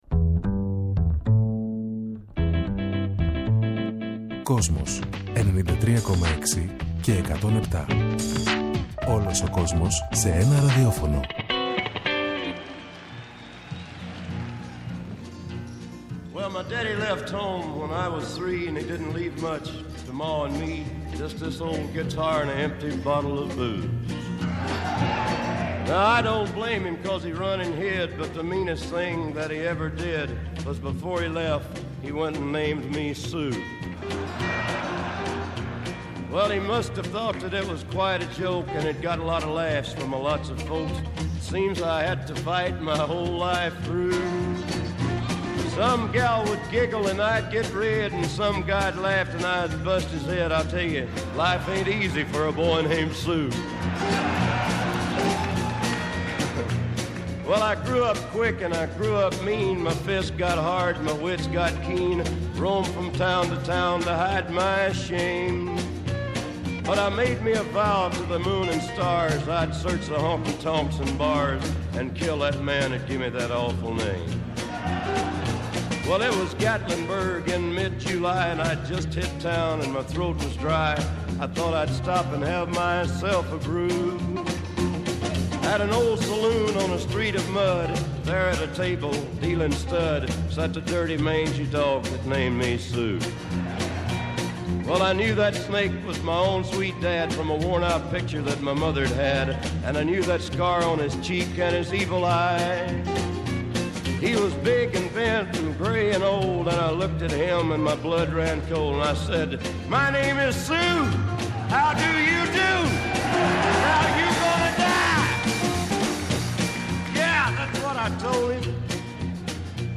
H Country στον Kosmos σας!